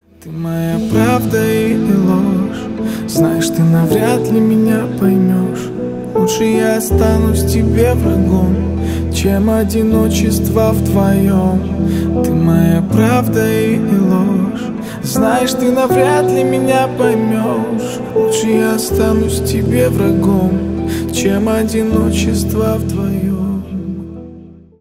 Поп Музыка
грустные # спокойные
кавер